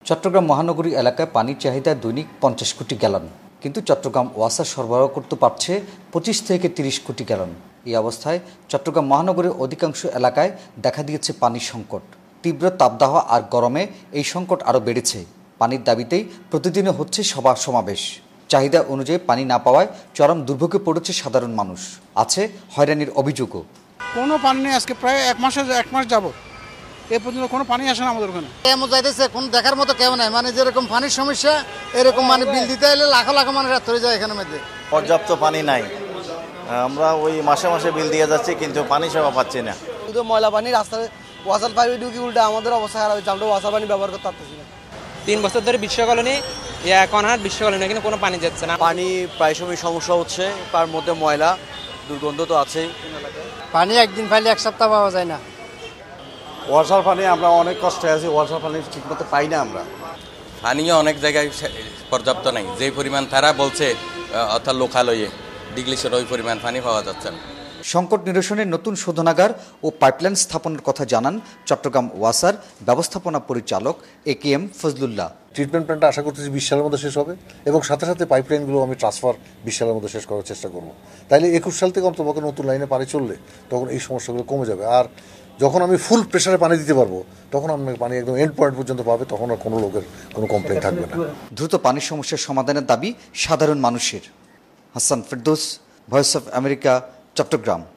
চট্টগ্রাম থেকে বিস্তারিত জানাচ্ছেন